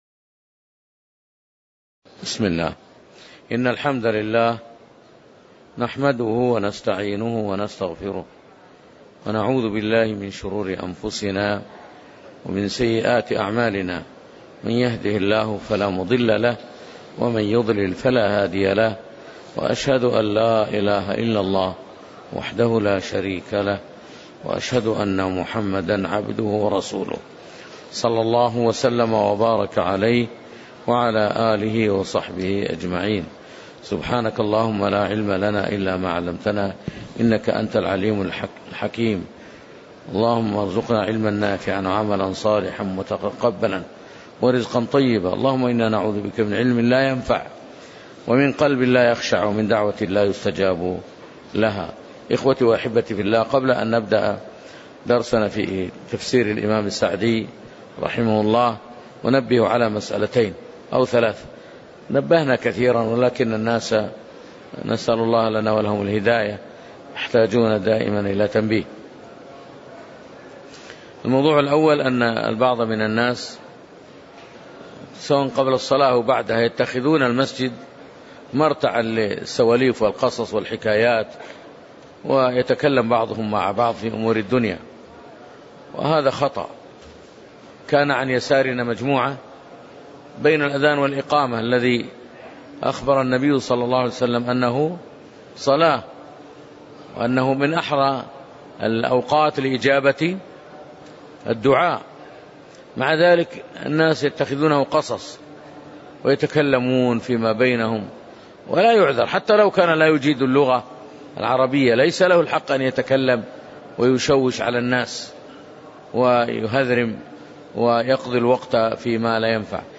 تاريخ النشر ١٩ ذو القعدة ١٤٣٨ هـ المكان: المسجد النبوي الشيخ